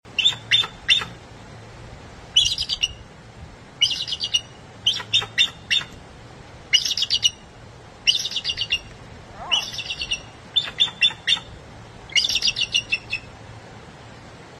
For such a powerful bird, the Bald Eagle has a surprisingly loud sounding call, usually a series of high-pitched whistling or piping notes.